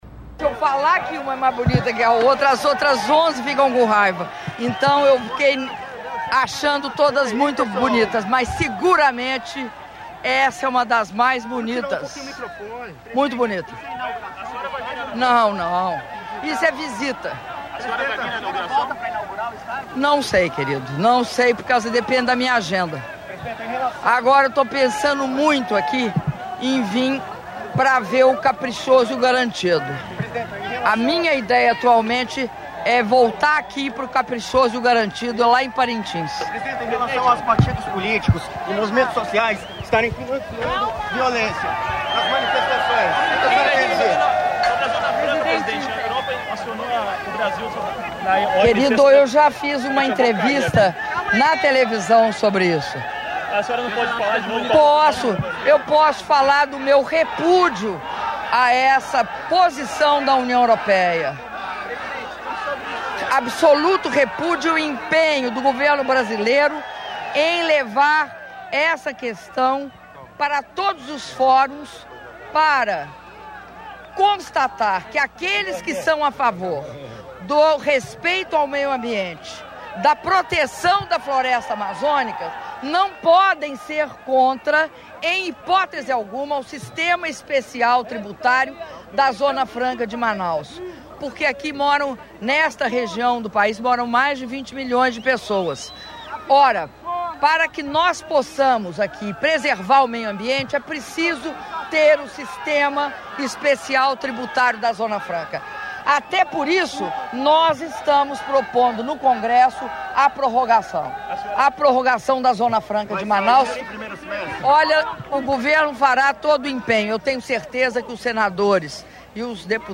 Áudio da entrevista coletiva concedida pela Presidenta da República, Dilma Rousseff, após visita às obras do Estádio Arena da Amazônia - Manaus/AM (02min19s)